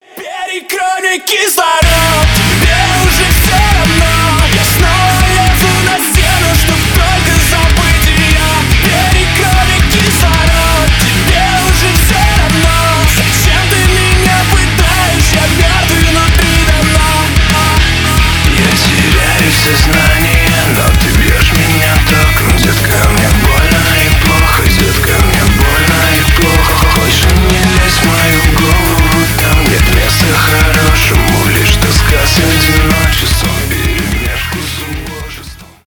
панк-рок
nu metal , альтернатива metal